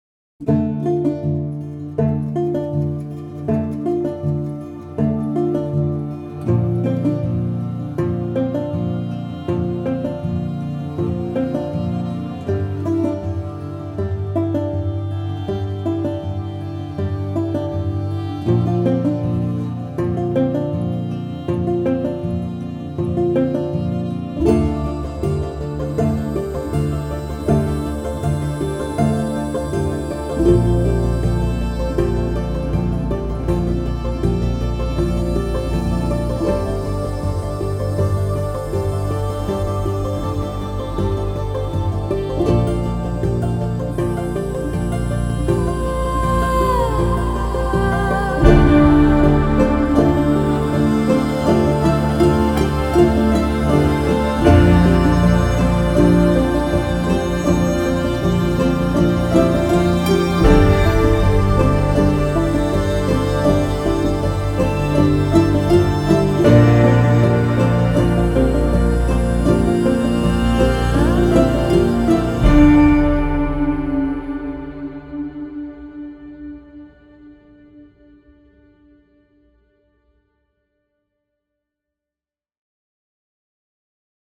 Vivid folk collection
Diverse string textures